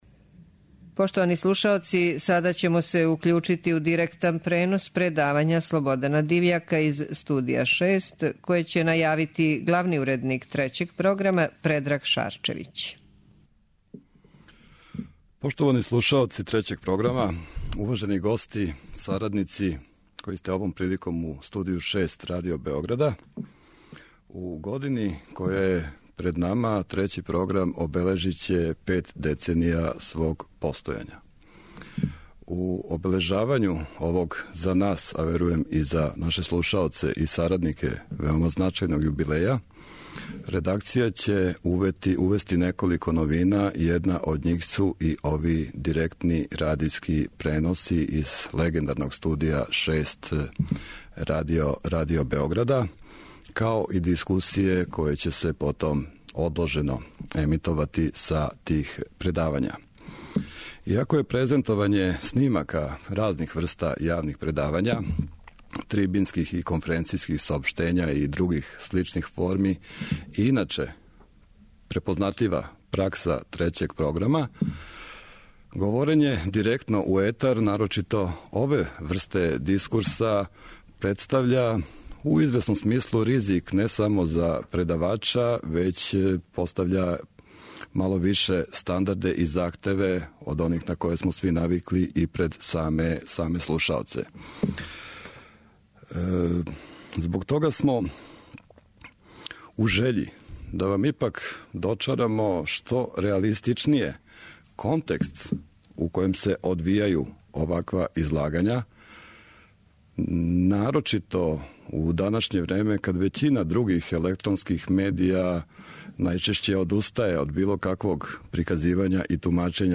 Ове године Трећи програм Радио Београда навршава пет деценија постојања. Обележавајући овај значајан јубилеј, редакција Програма организоваће серију предавања која ће се једном месечно одржавати у Студију 6 Радио Београда и директно преносити на нашим таласима, као и посредством Интернета.
Радијско предавање